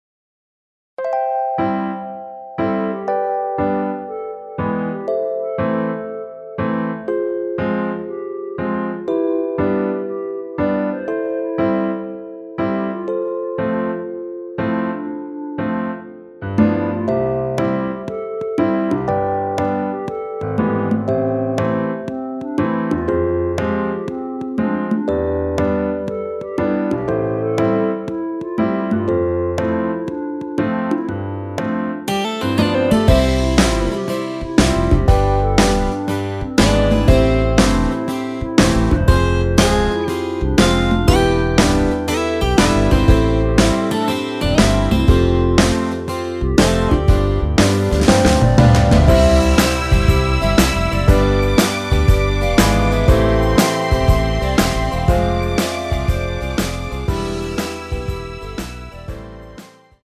남성분이 부르실수 있는 키로 제작 하였습니다.(미리듣기 참조)
엔딩이 페이드 아웃이라 라이브 하시기 좋게 엔딩을 만들어 놓았습니다.
원키에서(-6)내린 멜로디 포함된 MR입니다.
앞부분30초, 뒷부분30초씩 편집해서 올려 드리고 있습니다.